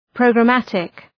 Προφορά
{,prəʋgrə’mætık}